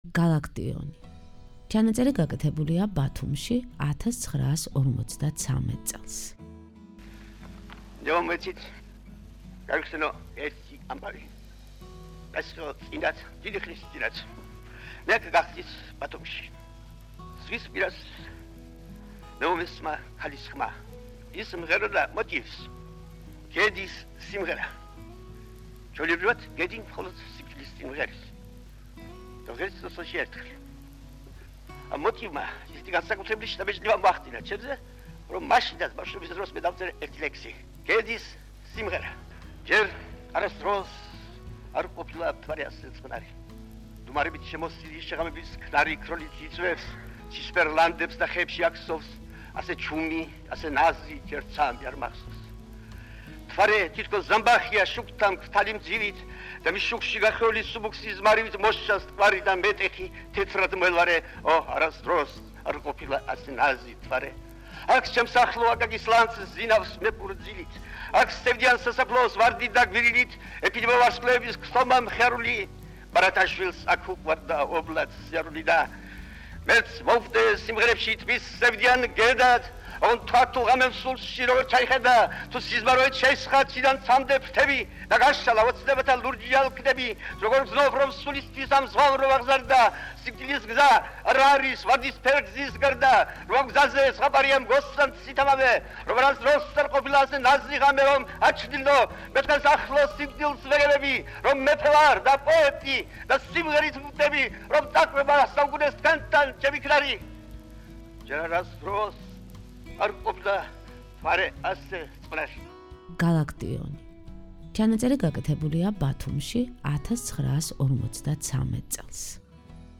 გალაკტიონი - მთაწმინდის მთვარე /ჩანაწერი გაკეთებულია 1953 წელს - /ბათუმში/